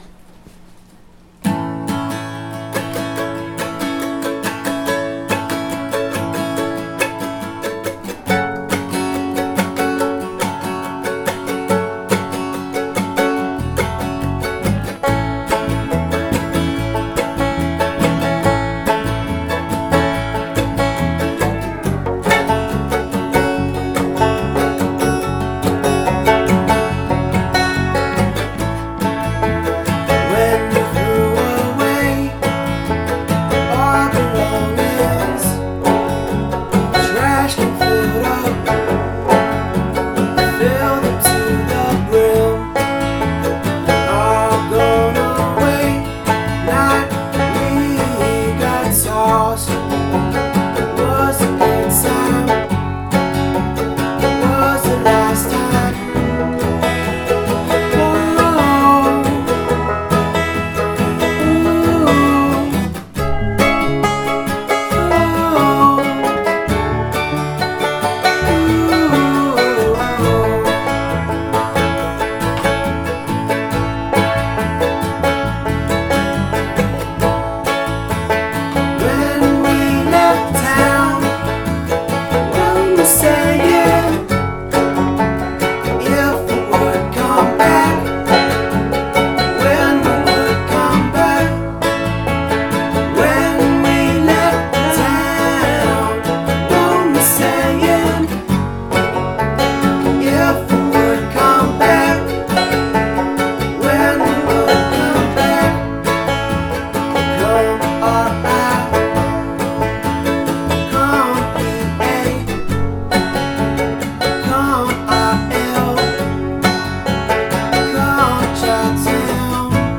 Goth Folk
textured by the rustic influence of harmonica and lap steel.